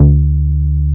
R MOOG D3P.wav